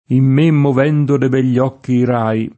im m% mmov$ndo de bHl’l’ 0kki i r#i] (Petrarca); Chinati i rai fulminei [